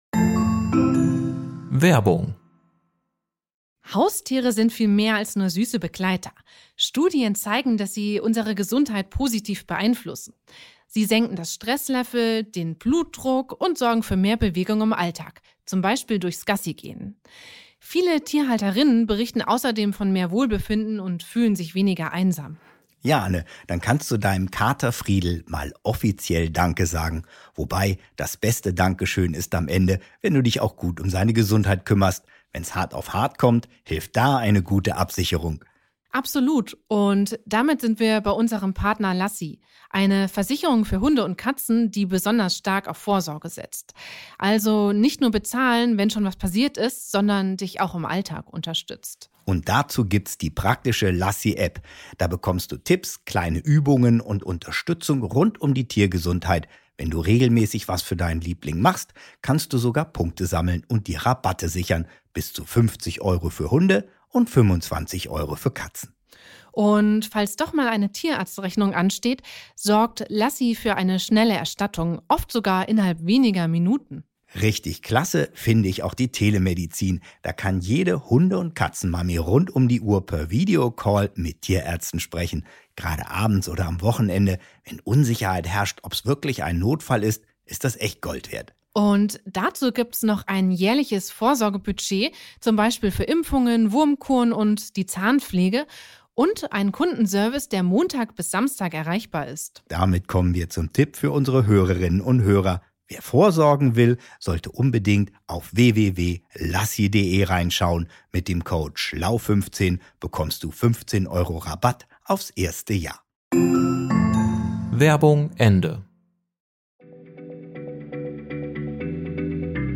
im Gespräch mit der Journalistin